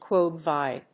/kwὰdvάɪdi(米国英語), kw`ɔdvάɪdi(英国英語)/